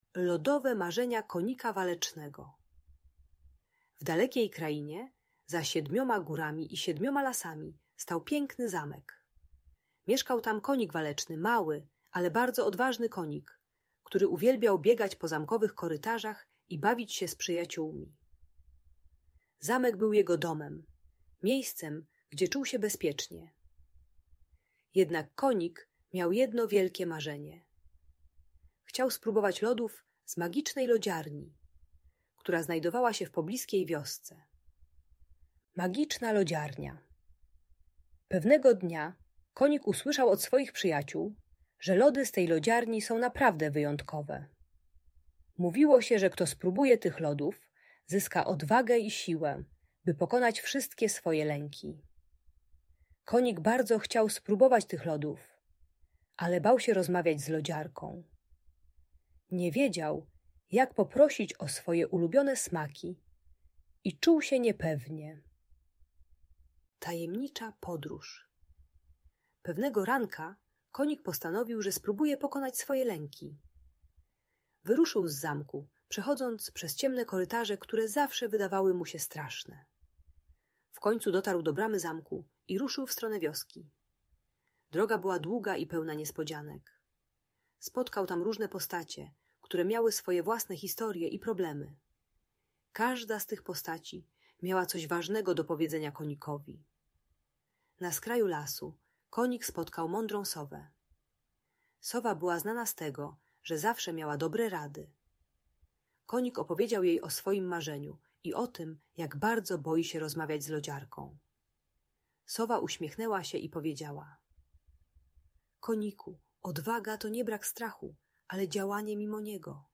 Lodowe Marzenia Konika Walecznego - Audiobajka dla dzieci